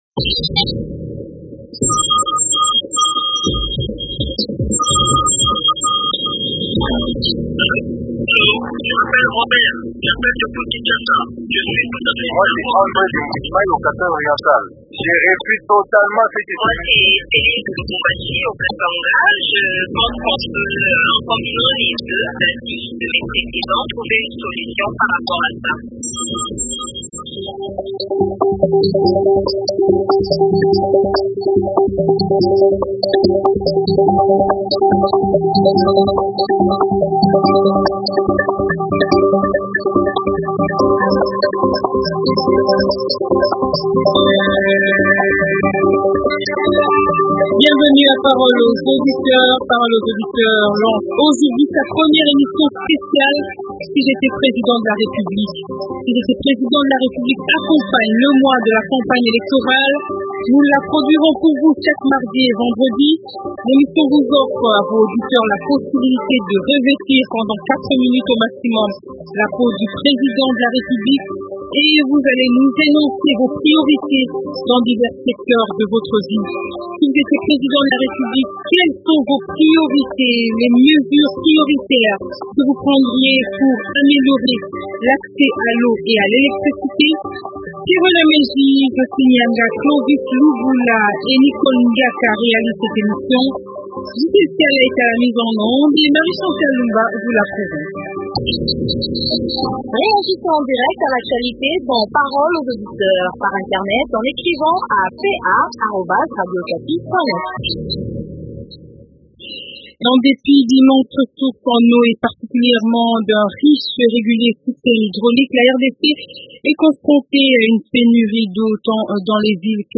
Si j’étais président de la République est l’émission spéciale « Paroles aux auditeurs » pour la campagne électorale. L’émission vous offre la possibilité de revêtir pendant 4 minutes au maximum la peau du président de la République et d’indiquer ce qui, d’après vous, constitue les priorités dans divers secteurs de la vie en RDC.